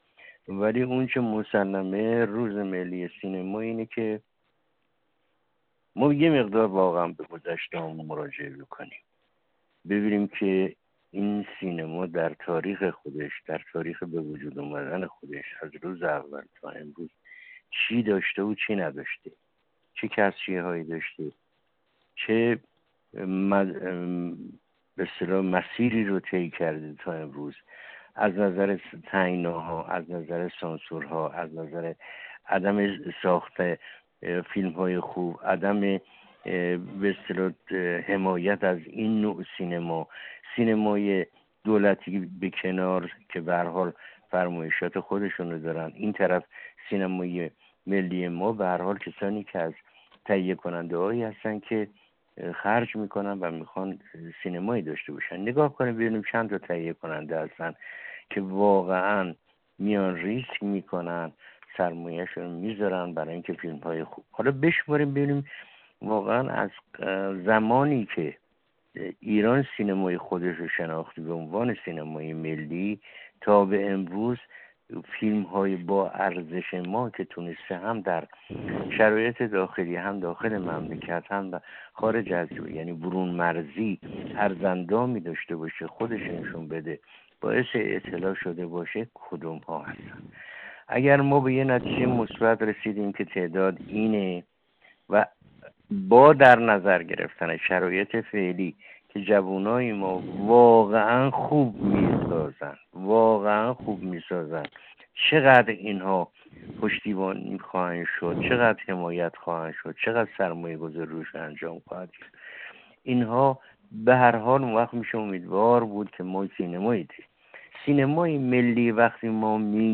گفت‌وگوی ایکنا با ابراهیم فروزش به مناسبت روز ملی سینما